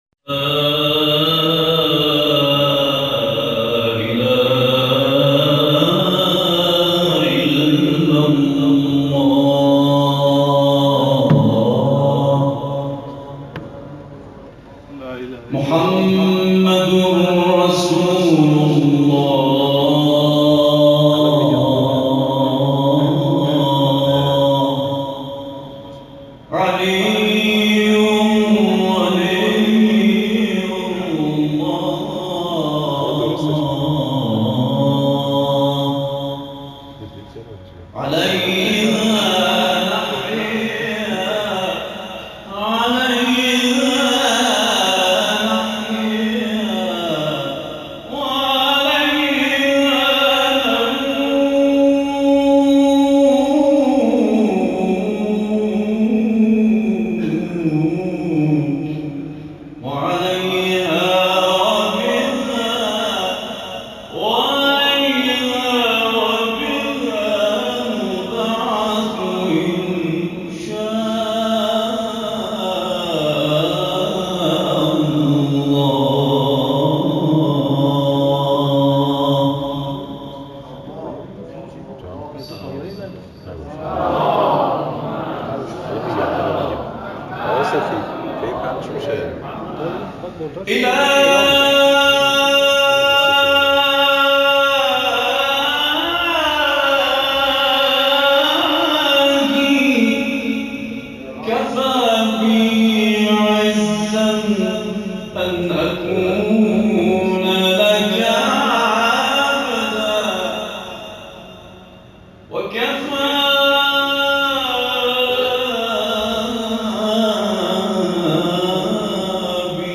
گروه فعالیت‌های قرآنی: محفل انس با قرآن کریم، شب گذشته، 12 تیرماه در شهر ری برگزار شد.
ابتهال خوانی